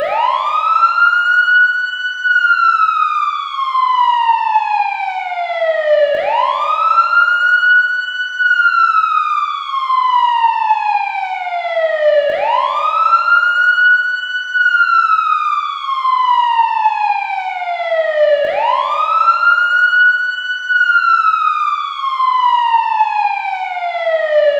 sirenout.wav